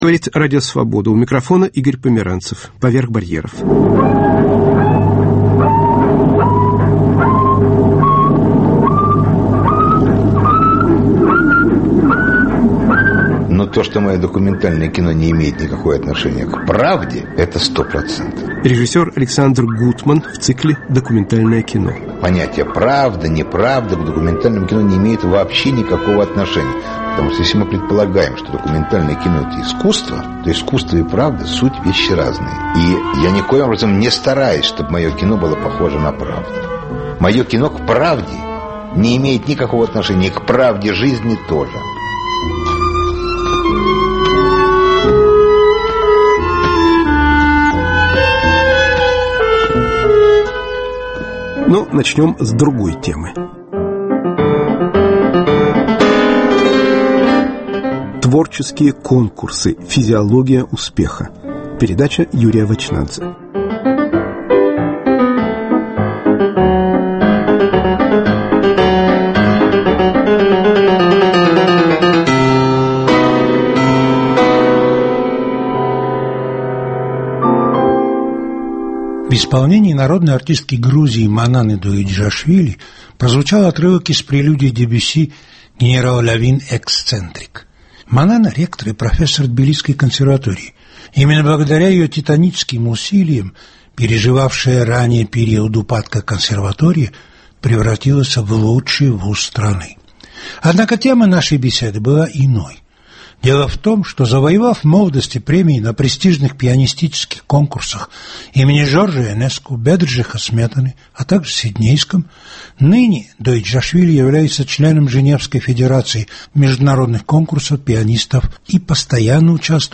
Говорят музыканты и историк культуры из Тбилиси.